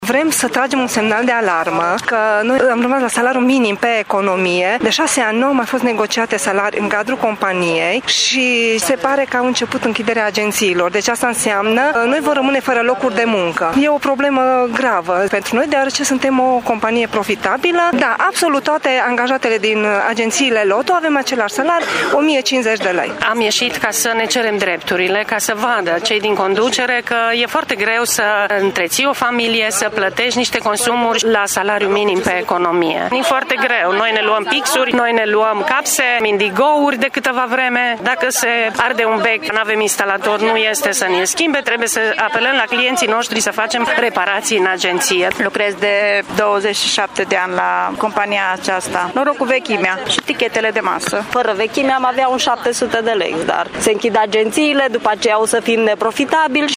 Circa 30 de sindicaliști care lucrează în agențiile LOTO din municipiu au pichetat sediul Prefecturii Mureș, nemulțumiți de situația gravă în care se află compania.
Sindicaliștii sunt nemulțumiți că după zeci de ani de muncă au salariul minim pe economie și trebuie să aducă de acasă consumabile sau să apeleze la clienți pentru mici reparații în sediile agențiilor: